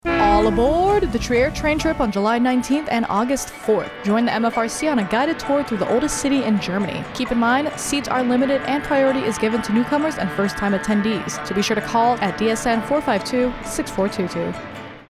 A 15 second spot for AFN Spangdahlem advertising the Trier Train Trip with the MFRC.